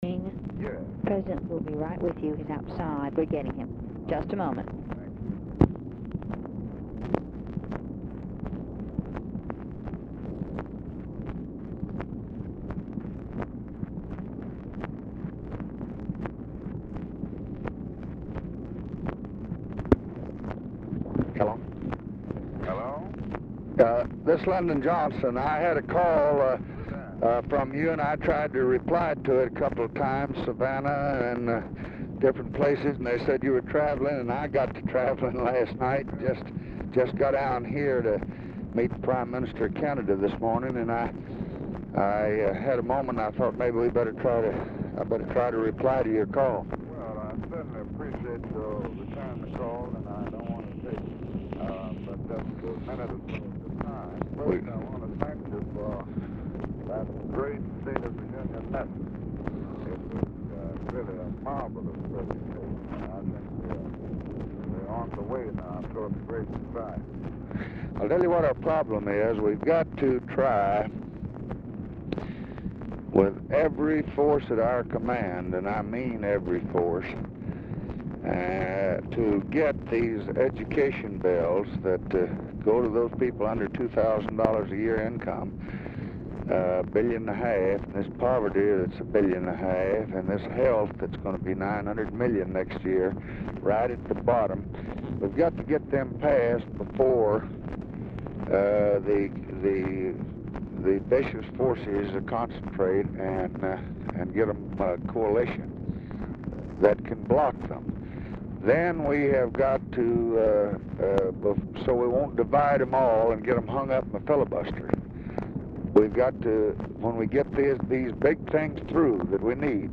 MLK IS DIFFICULT TO HEAR; OFFICE SECRETARY TELLS MLK LBJ IS COMING TO THE TELEPHONE
Format Dictation belt
Location Of Speaker 1 LBJ Ranch, near Stonewall, Texas
Specific Item Type Telephone conversation